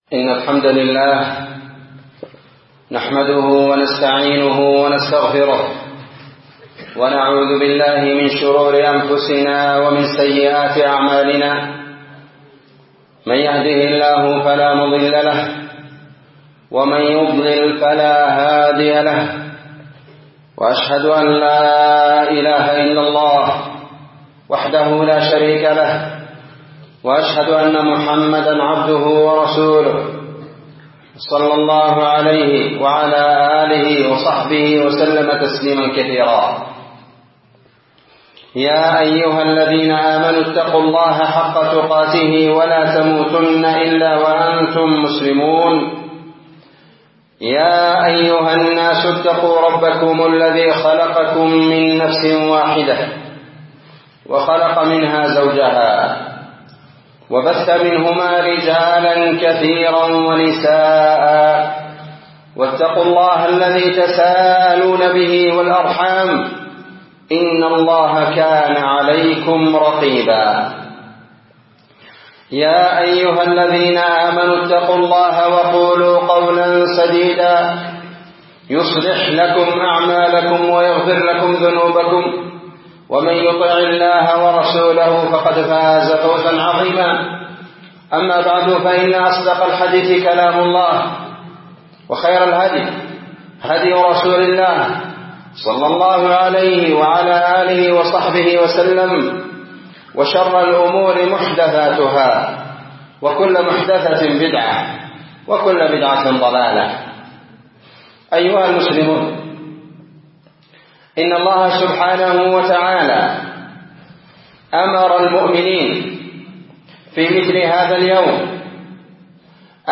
خطبة بعنوان تفسير سورة التكاثر 13 جمادى الآخرة 1444